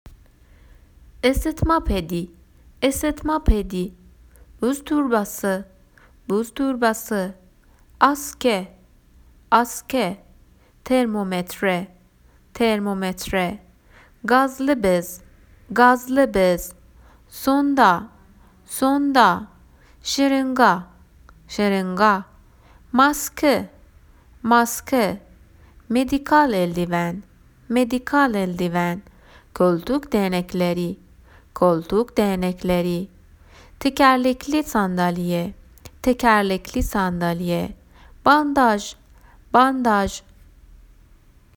تلفظ اصطلاحات تجهیزات پزشکی به ترکی استانبولی